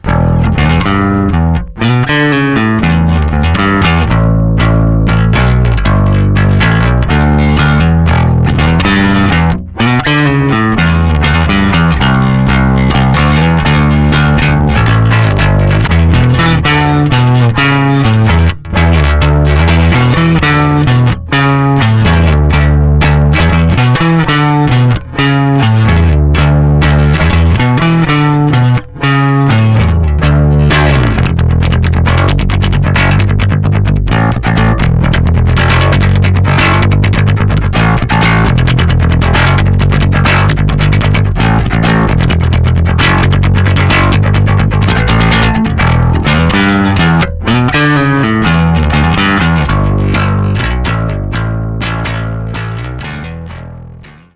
Einer von meinen absoluten Playern ist dieser Charvel 2B von 1986.
Kurzes Demo in den Royale TS, über die eingebaute Speaker Sim per DI ins Interface.
RoyaleTS-Charvel2B.mp3